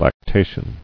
[lac·ta·tion]